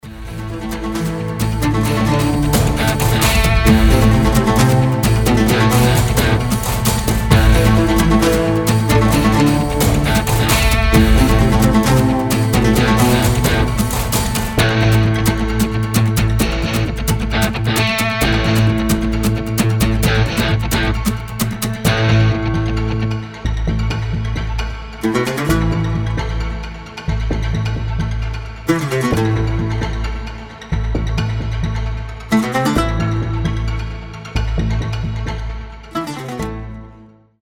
ost , инструментальные , без слов